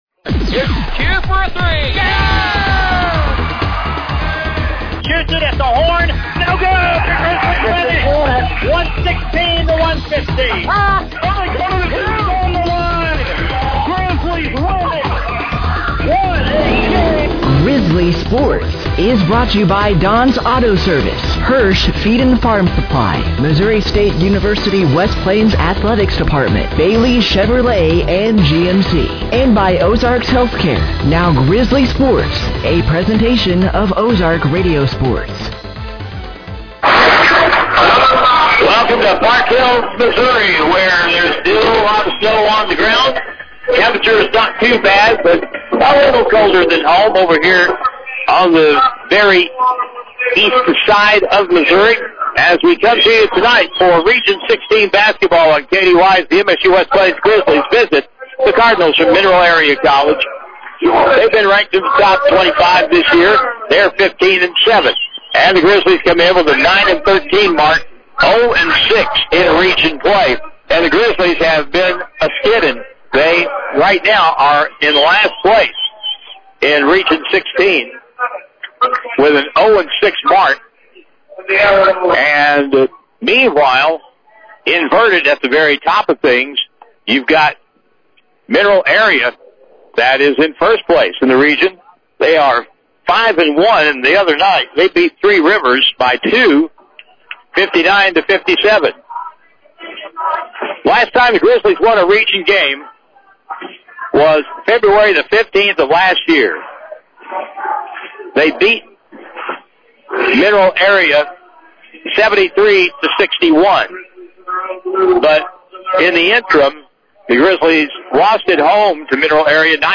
The Missouri State West Plains Grizzly Basketball team traveled to The Seacrest Event Center @ Mineral Area College in Park Hills, Missouri on Wednesday night, February 4th, 2026 to take on The Mineral Area Cardinals for the second time this season